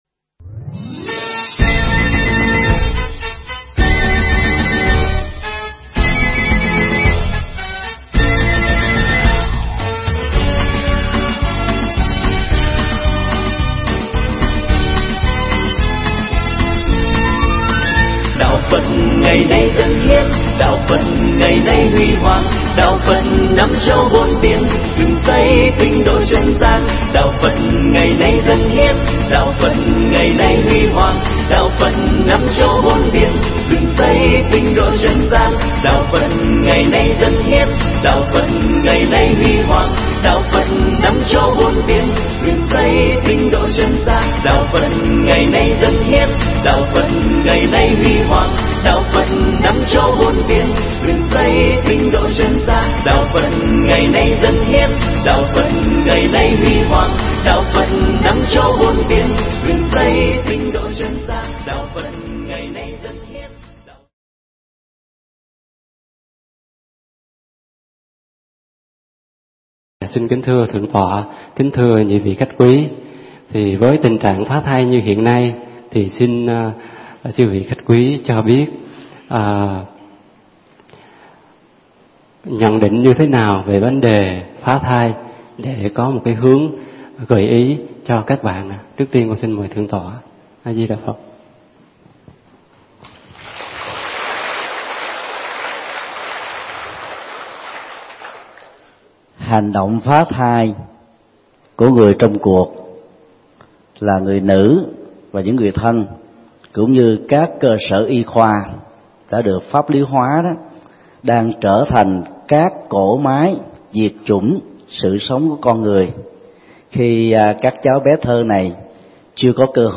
Mp3 Thuyết Giảng Mẹ ơi đừng giết con: Phòng ngừa nạo phá thai – Thầy Thích Nhật Từ giảng tại chùa Hoàng Pháp, Hóc Môn, TP. Hồ Chí Minh, ngày 17 tháng 6 năm 2014